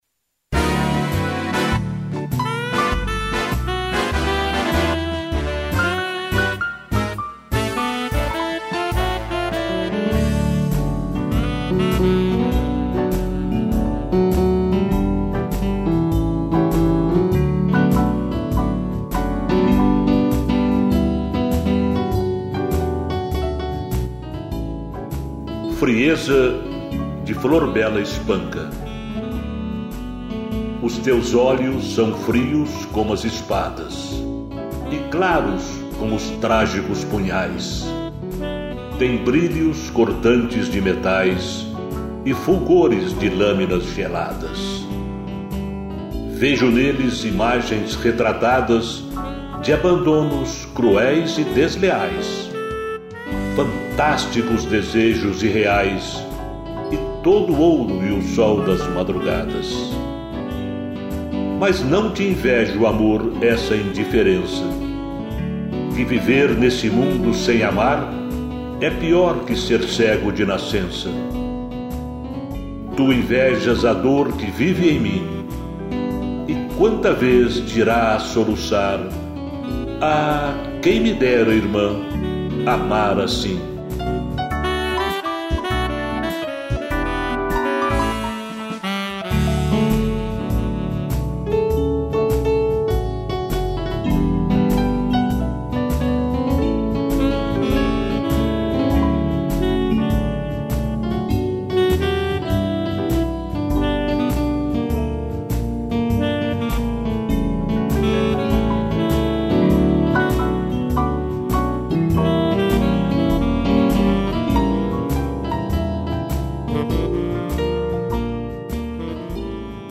piano e harmônica